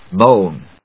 /móʊn(米国英語), mˈəʊn(英国英語)/